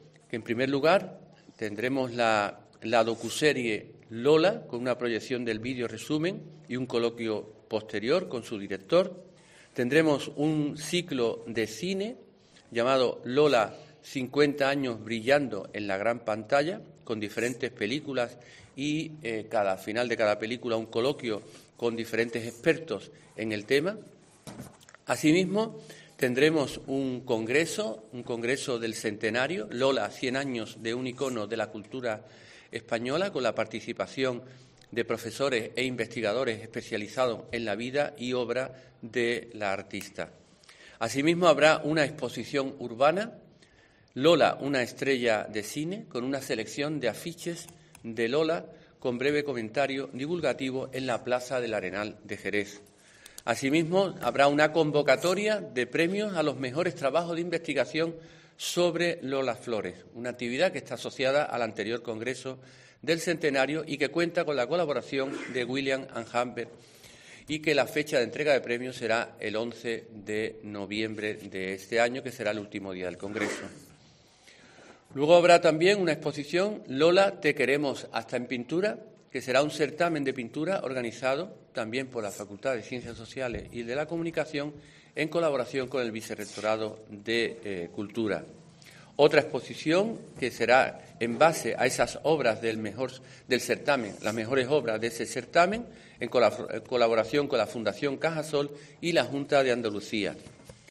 Escucha aquí al rector de la UCA, Francisco Piniella, con el detalle de las actividades que promueven al respecto